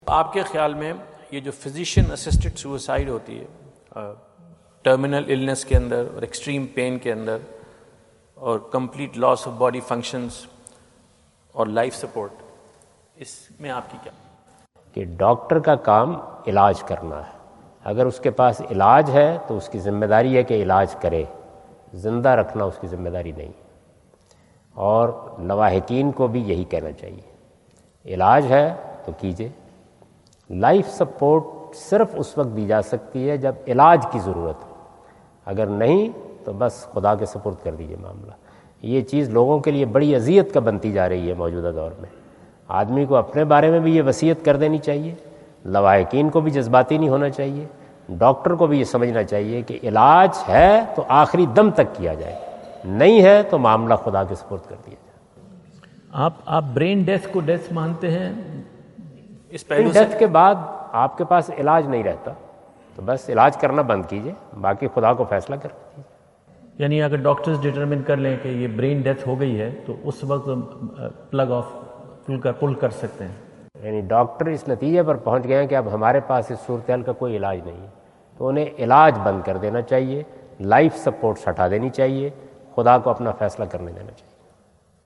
Javed Ahmad Ghamidi answer the question about "Significance of Physician Assisted Suicide in Islam" During his US visit at Wentz Concert Hall, Chicago on September 23,2017.
جاوید احمد غامدی اپنے دورہ امریکہ2017 کے دوران شکاگو میں "اسلام میں طبی طریقے سے خود کشی کی حیثیت" سے متعلق ایک سوال کا جواب دے رہے ہیں۔